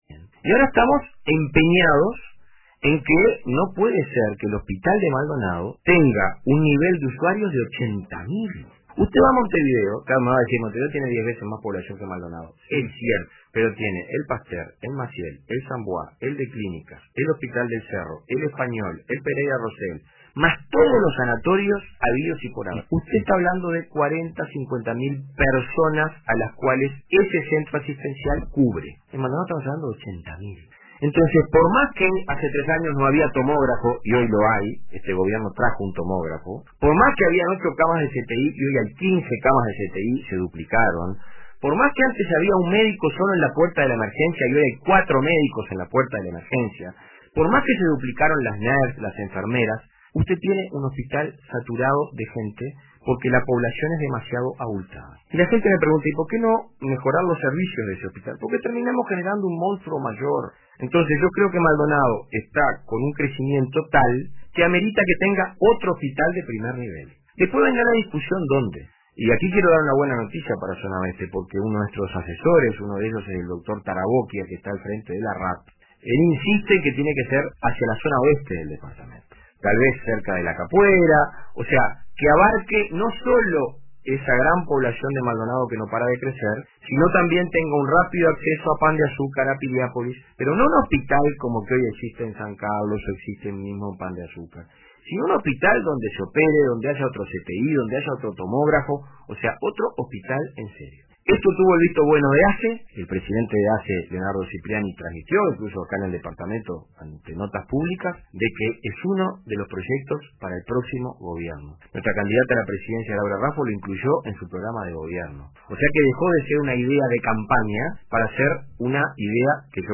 El Diputado Dr. Federico Casaretto participó en el programa “RADIO CON TODOS” de RADIO RBC.